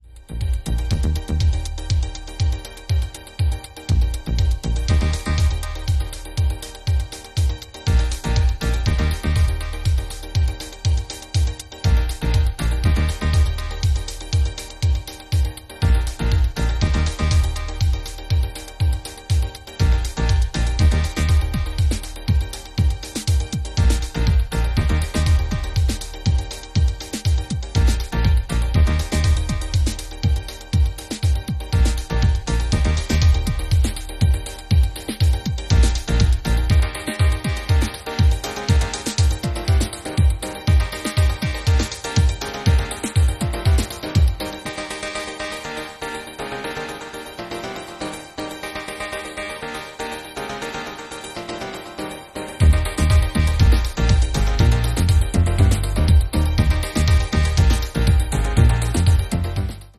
on vinyl
acid house